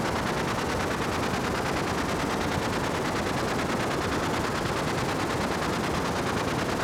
STK_MovingNoiseB-140_01.wav